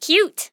8 bits Elements
Voices Expressions Demo
Cute.wav